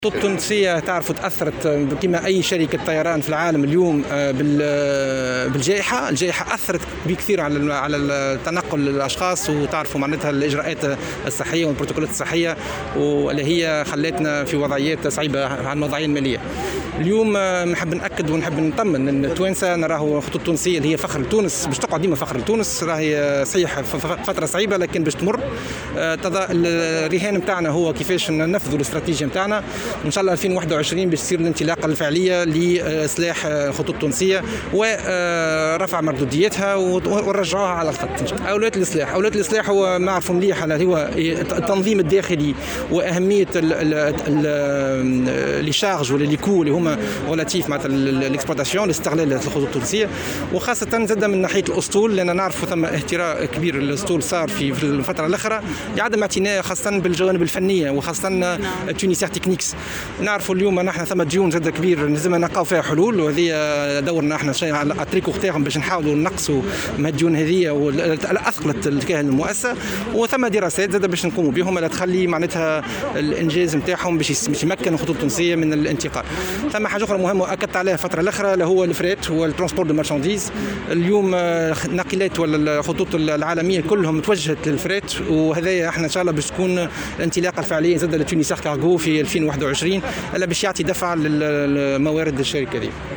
وأضاف في تصريح لمراسل "الجوهرة أف أم" على هامش إشرافه على تظاهرة "أجنحة المدينة" التي انتظمت بمدينة العلوم بتونس، أن الناقلة الوطنية مرّت بفترة صعبة وأن من أولويات عملية الإصلاح، إعادة الهيكلة و تجديد الاسطول وإيجاد حلول للديون التي أثقلت كاهل الشركة.